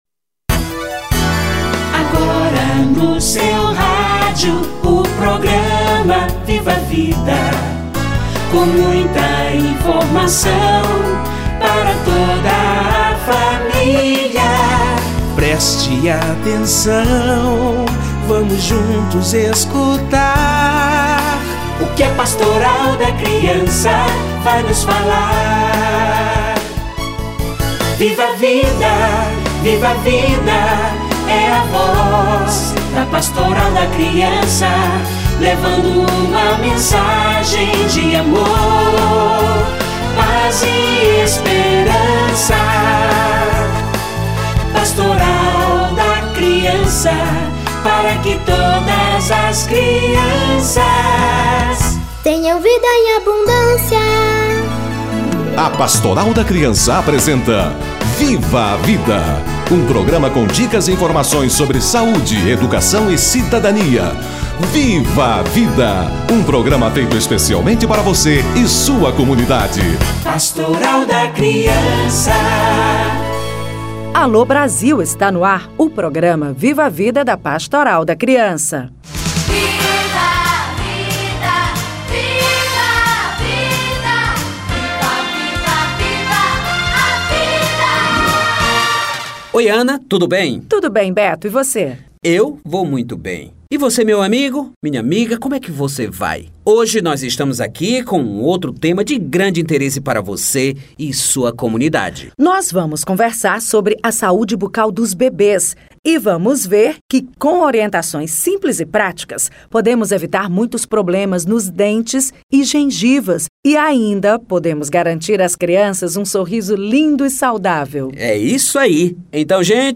Higiene bucal da criança - Entrevista